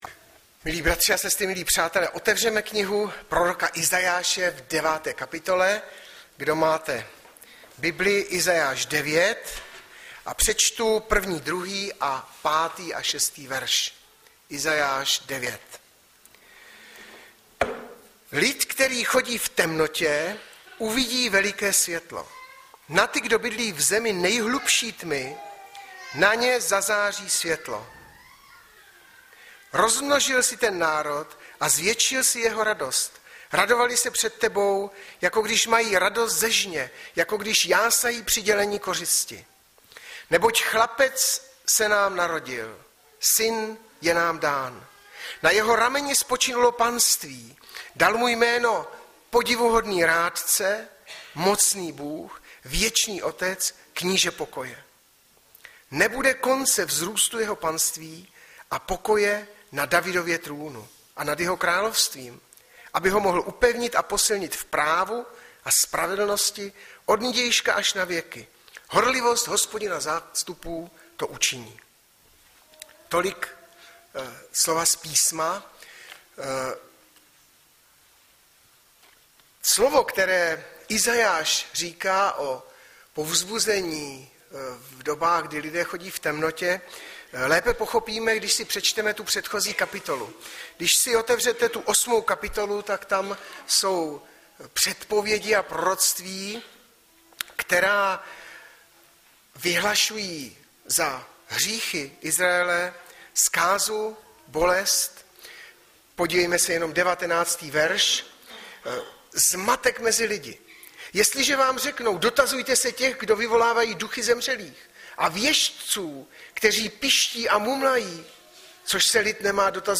23.12.2012 - DĚTSKÁ SLAVNOST | SPLNĚNÁ PROROCTVÍ - Iza 9,6
Audiozáznam kázání